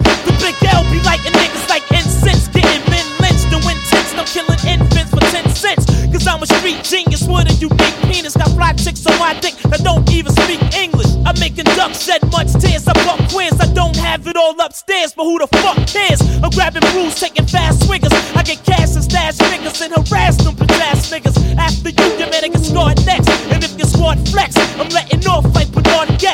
• Качество: 110, Stereo
ганста рэп